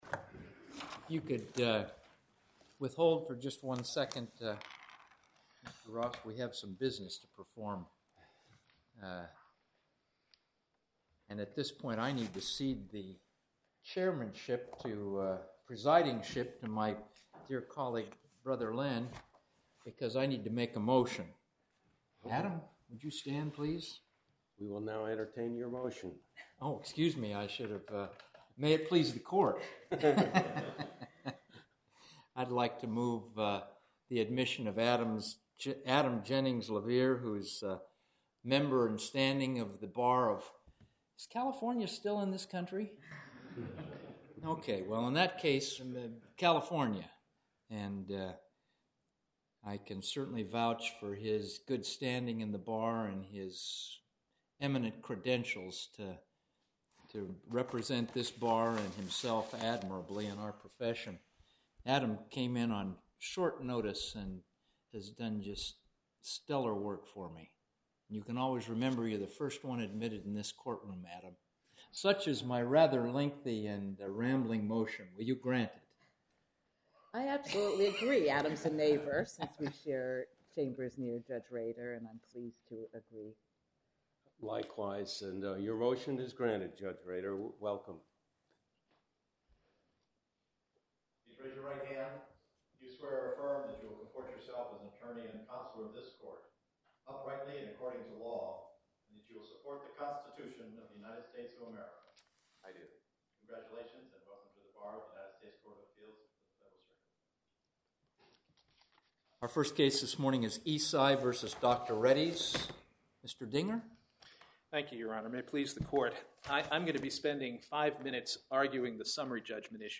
Oral argument audio posted: Eisai Co v Dr Reddy’s (mp3) Appeal Number: 2007-1397 To listen to more oral argument recordings, follow this link: Listen To Oral Arguments.